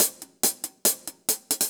Index of /musicradar/ultimate-hihat-samples/140bpm
UHH_AcoustiHatC_140-03.wav